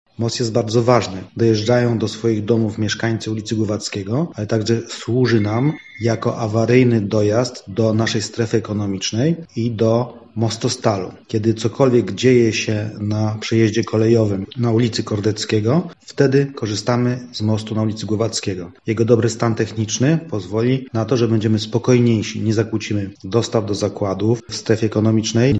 Burmistrz Rudnika Waldemar Grochowski przyznaje, że most jest ważny nie tylko dla mieszkańców, ale i dla przedsiębiorców: